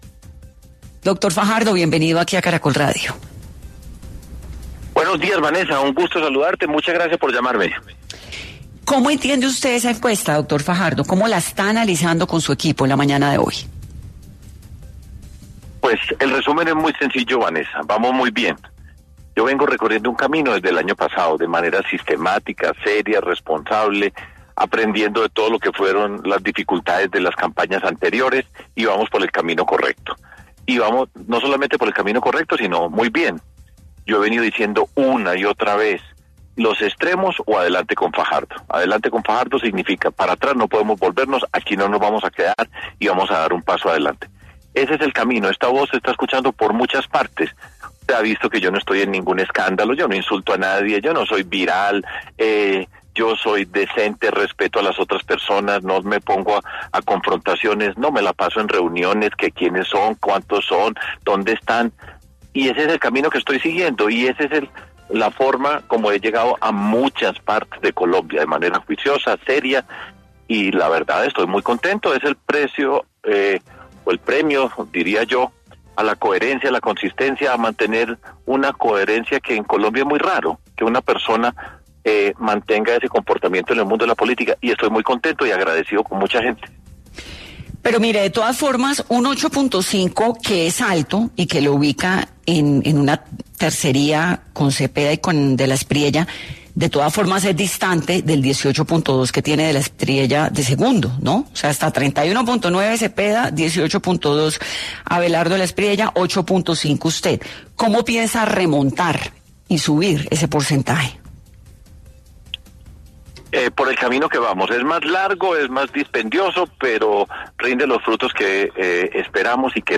Sergio Fajardo analizó en 10AM los resultados de la encuesta Invamer, donde a él lo ubican como uno de los favoritos